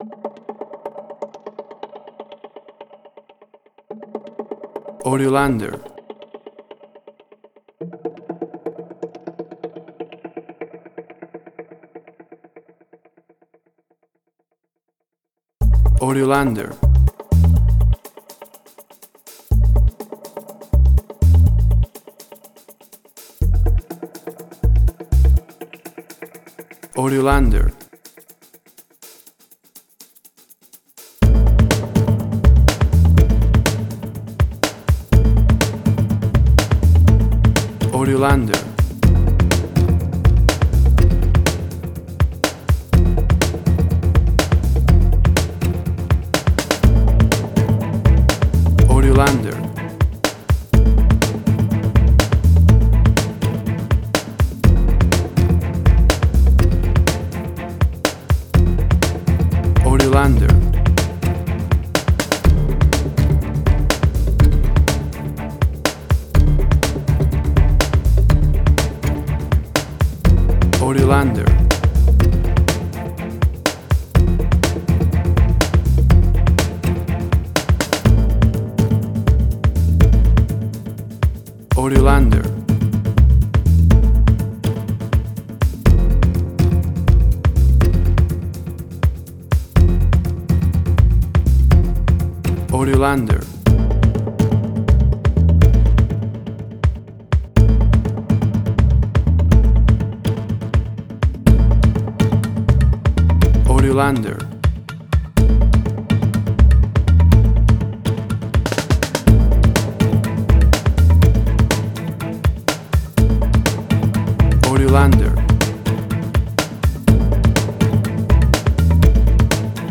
Suspense, Drama, Quirky, Emotional.
WAV Sample Rate: 16-Bit stereo, 44.1 kHz
Tempo (BPM): 123